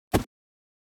Add combat sfx
swing.wav